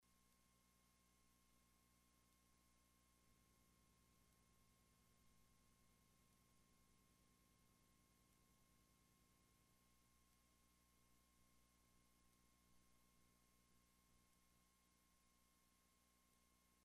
Noise Issues...
I've had a Home Studio for some time now and have recently noticed noise in my system. It is always present- and even picks up my mouse/ keyboard movements.